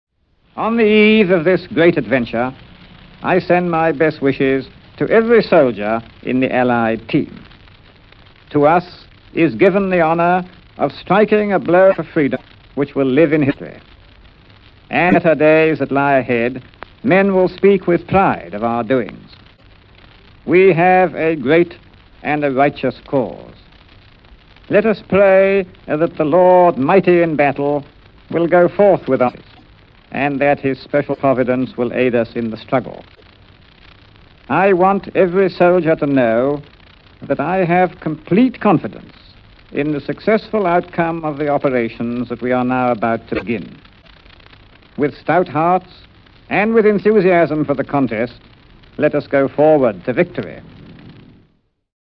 Jeff Montgomery Links Current stats and news: From rotonews Bio: From the Royals website It's...: Monty Python's Flying Circus Sound Bite: General Montgomery addresses the troops on the eve of D-Day Back To Zingrods Roster: